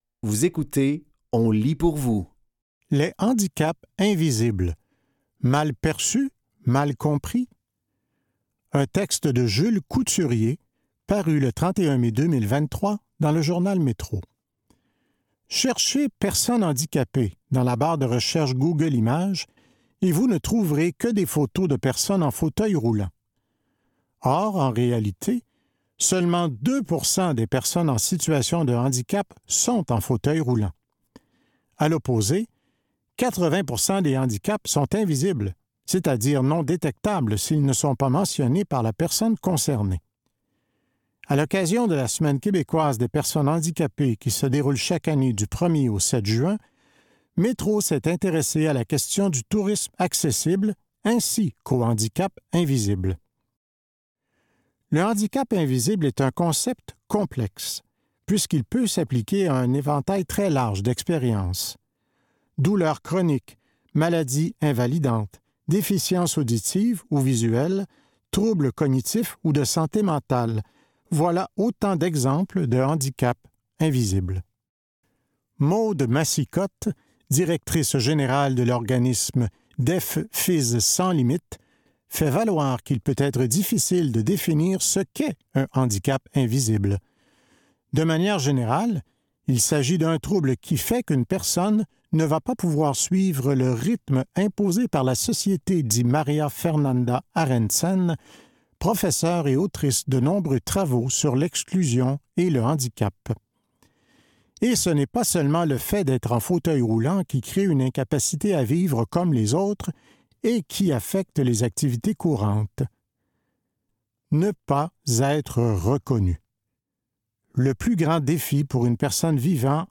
Dans cet épisode de On lit pour vous, nous vous offrons une sélection de textes tirés des médias suivants : Journal Métro, ICI Grand Nord, Fugues et La Presse.